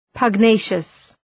Προφορά
{pəg’neıʃəs}